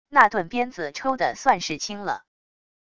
那顿鞭子抽的算是轻了wav音频生成系统WAV Audio Player